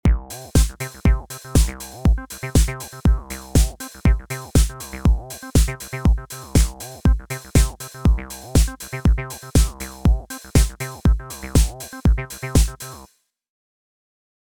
Play with Odd-Length Patterns for Polyrhythms
Shortening your TB-303 pattern to an odd number of steps (e.g., 9, 11, 13) creates a loop that shifts over time, adding movement to your track.